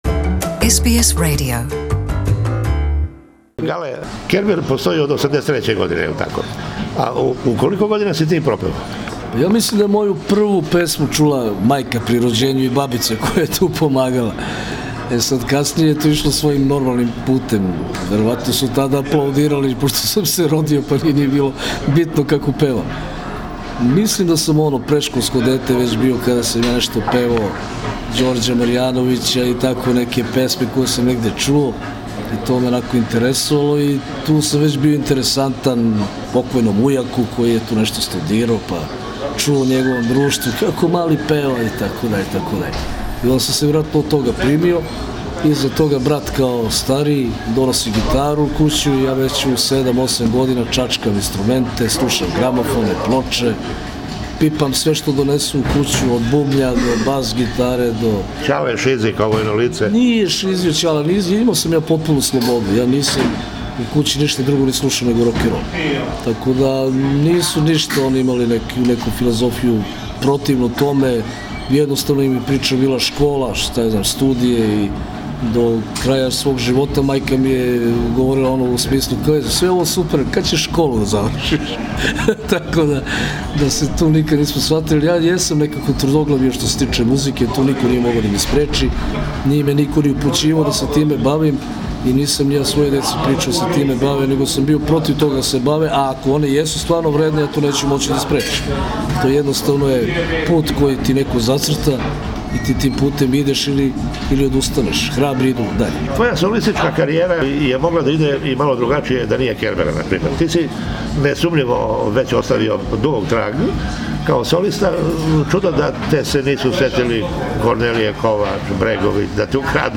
Интервју са фронтменом групе „Кербер“ Гораном Шепом Галетом